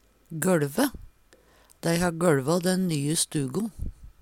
gøLve - Numedalsmål (en-US)